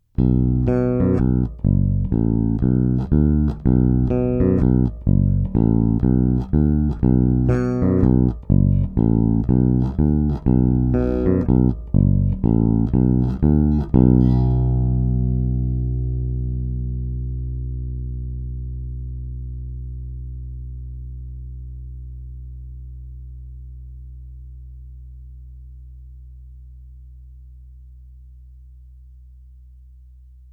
Dlouhý sustain, plný basový vrnivý zvuk.
Teprve po přidání basů a výšek dostanete ten správný stingrayovský charakteristický punch (viz ukázky níže).
Zvukové ukázky (nové tovární struny)
Ekvalizér úplně stažený